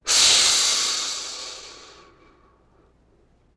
crocodile-sound